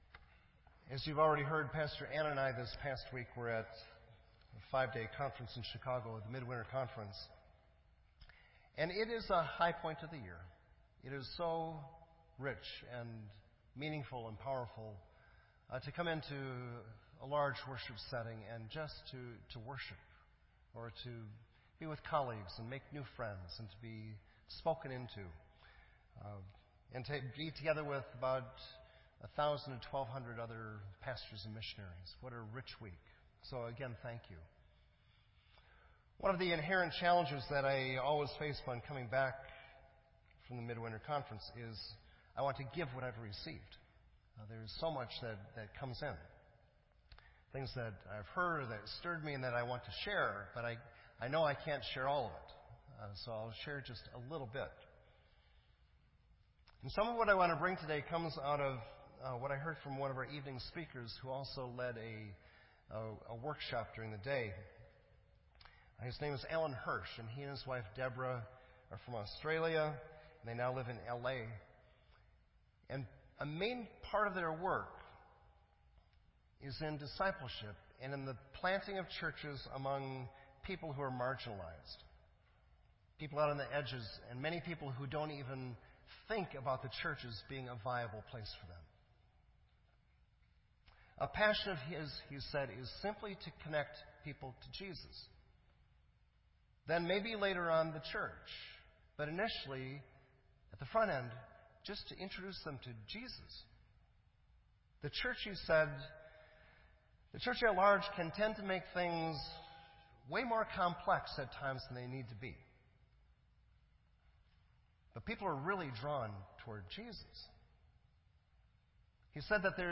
This entry was posted in Sermon Audio on January 29